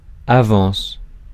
Ääntäminen
Synonyymit avancement acompte Ääntäminen France: IPA: [a.vɑ̃s] Haettu sana löytyi näillä lähdekielillä: ranska Käännös Ääninäyte Substantiivit 1. advance US 2. advance payment 3. retainer Suku: f .